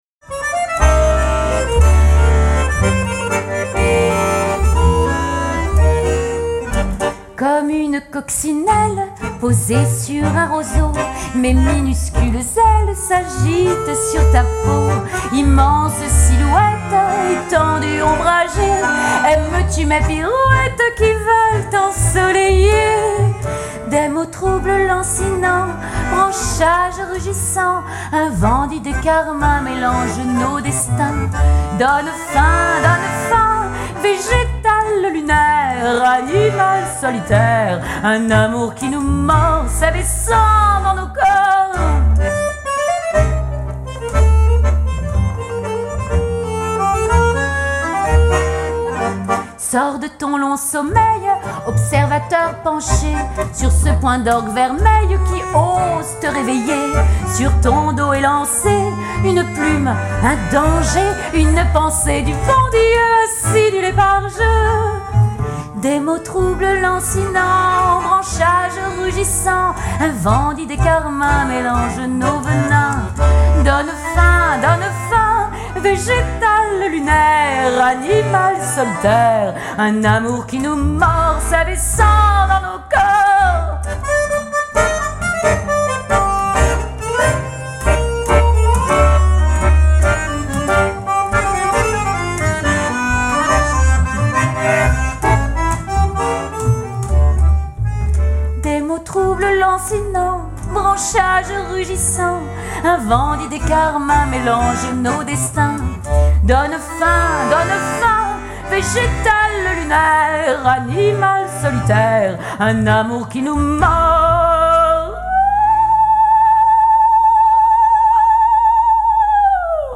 LES CHANSONS TANGOS